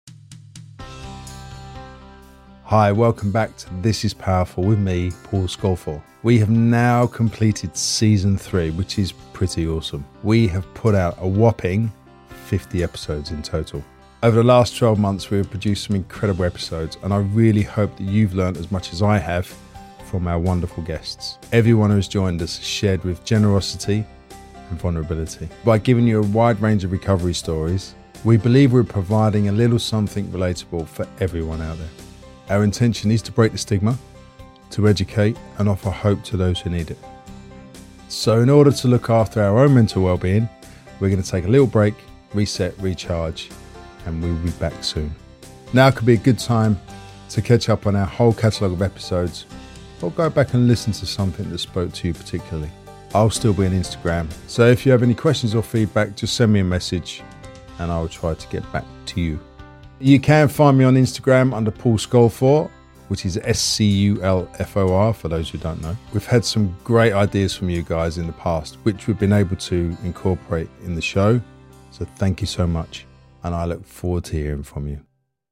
In this series, distinguished British model and mindset coach, Paul Sculfor comes clean about his own sobriety and the journey it took him to get here. Each week, Paul speaks to some incredible people about mindset, mental health, addiction and recovery.